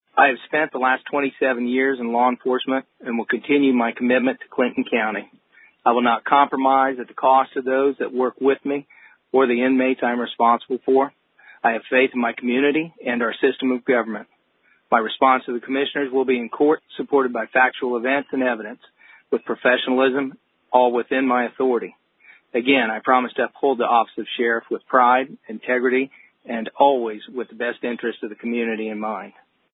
Sheriff Rich Kelly Issues Statement